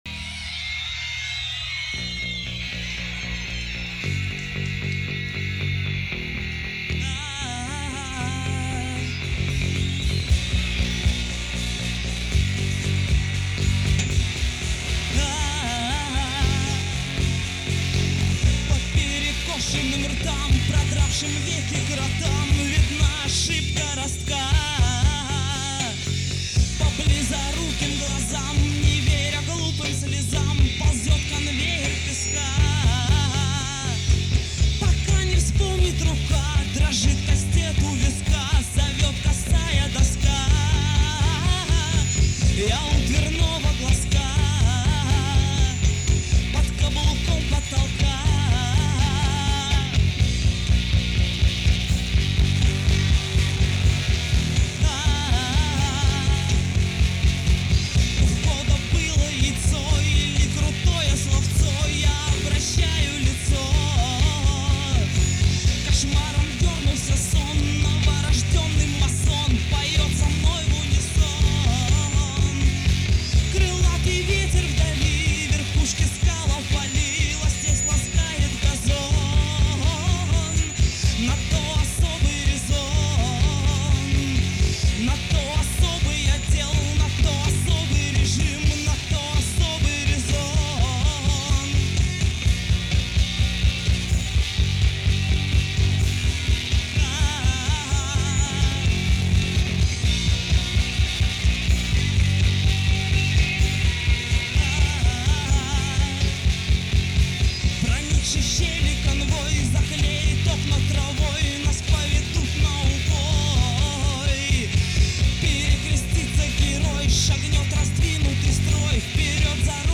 советская поэтесса, рок-певица и автор-исполнитель.
(Live)